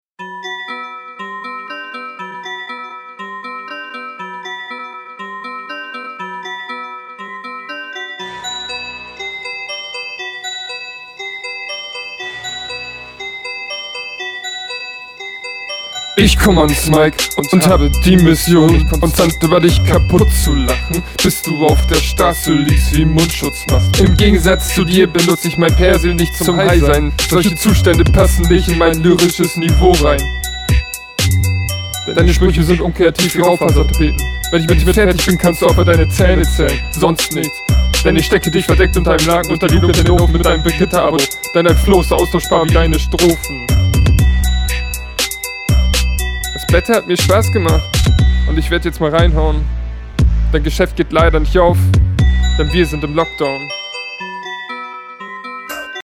Fang doch gleich an einfach zu sprechen.